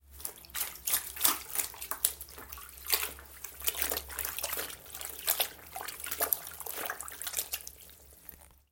水花飞溅
描述：一些水溅到金斯敦安大略湖的岩石上
Tag: 海浪 拍打 岸边